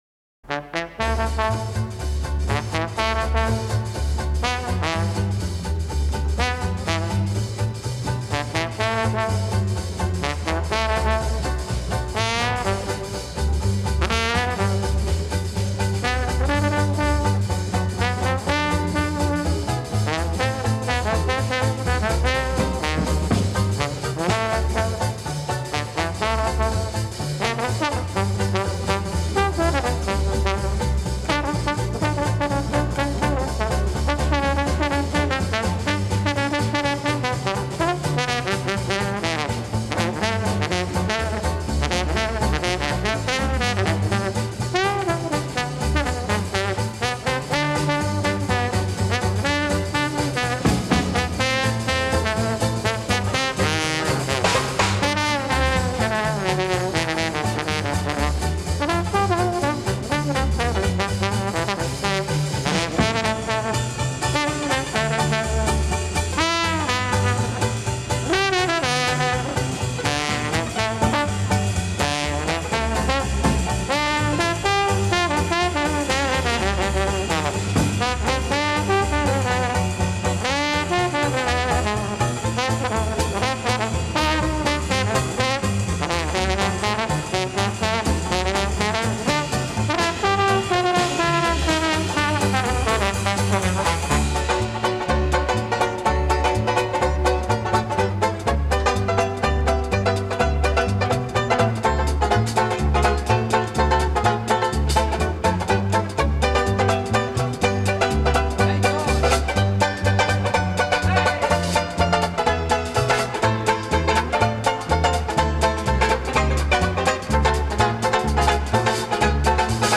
Веселые диксиленды послушаем .
диксиленд